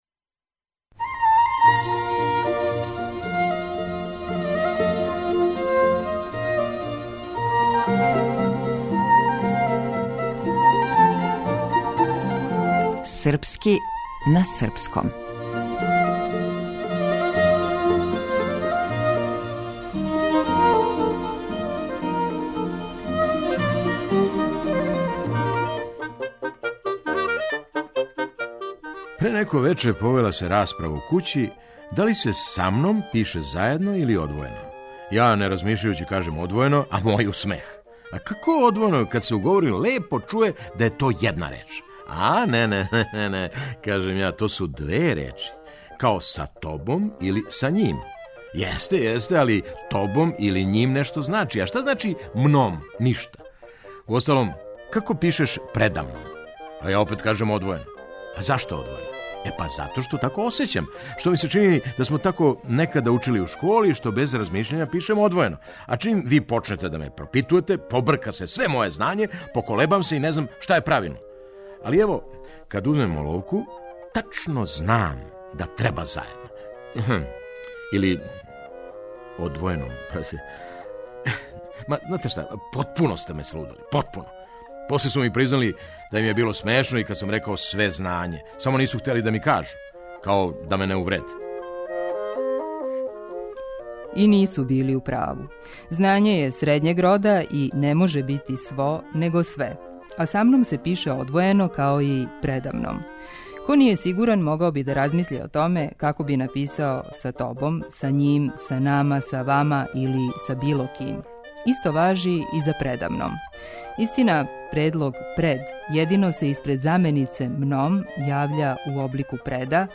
Драмски уметник: Феђа Стојановић